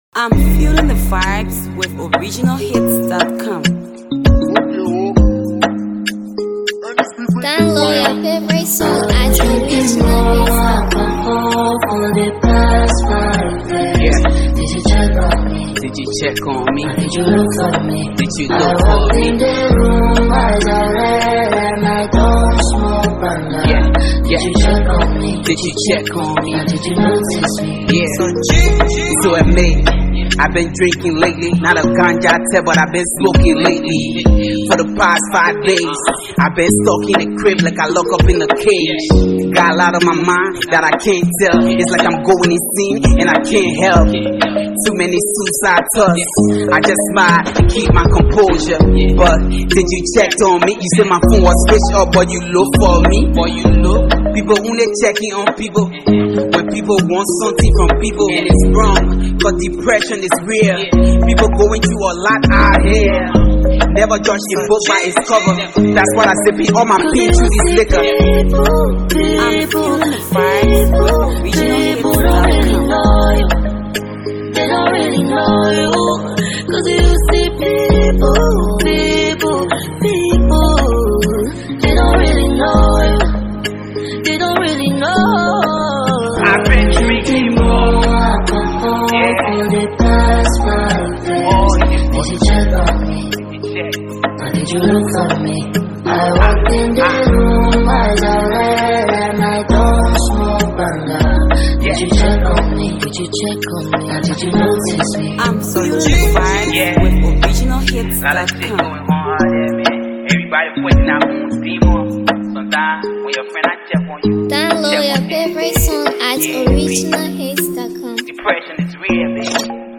a real heart-touching banger.